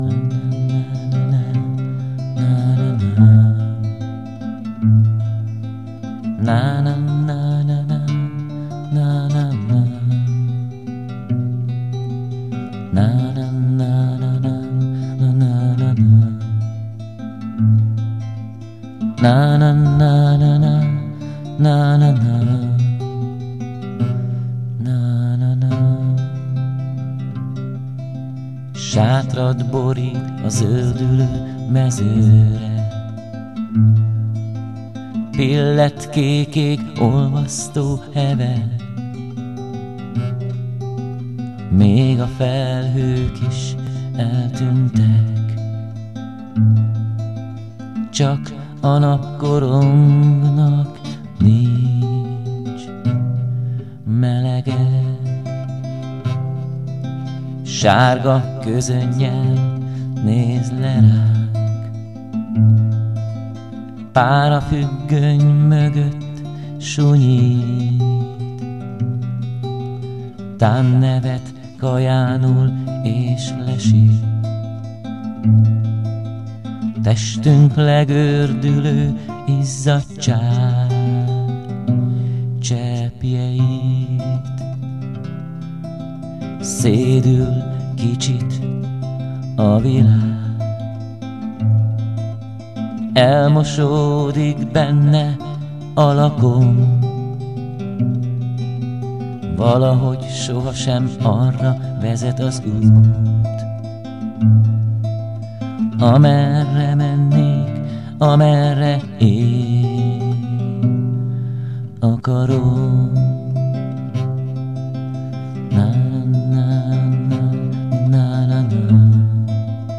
ének, furulya
gitár
hegedű